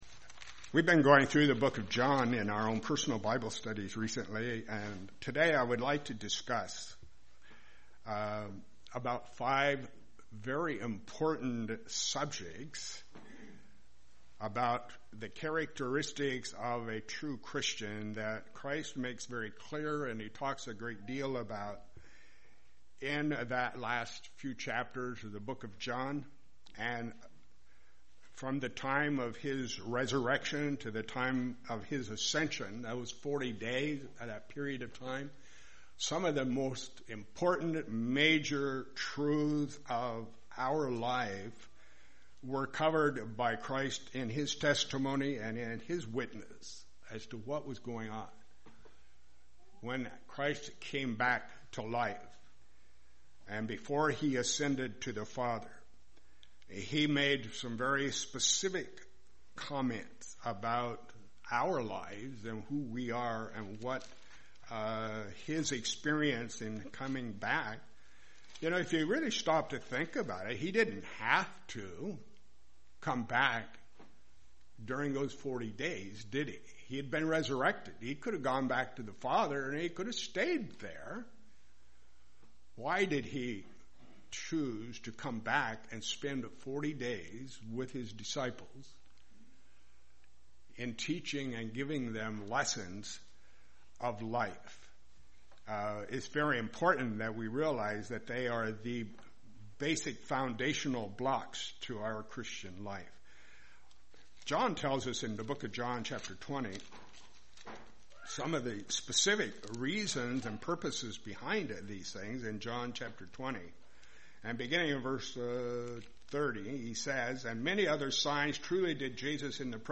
Sermons
Given in Lehigh Valley, PA Lewistown, PA